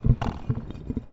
Rattle1.ogg